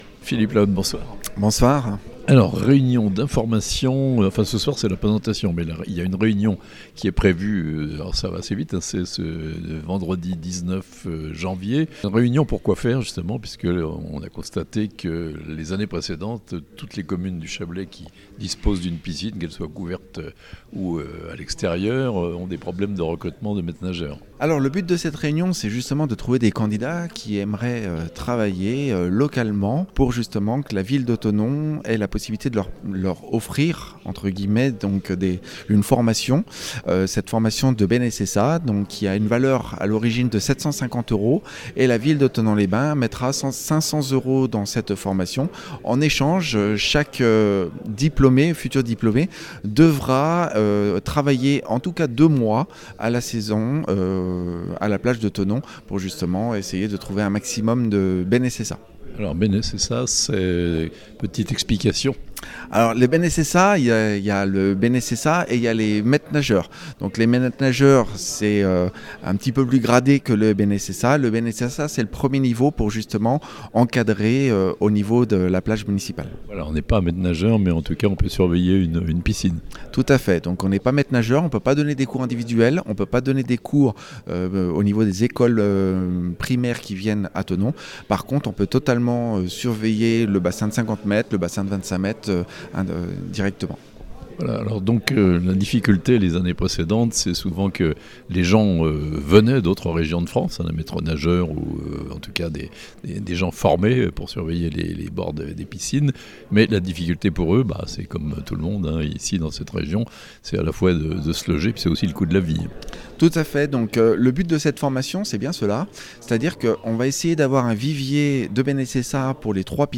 Pour la prochaine saison estivale les communes d'Evian, de Publier et de Thonon recherchent des sauveteurs aquatiques (interview)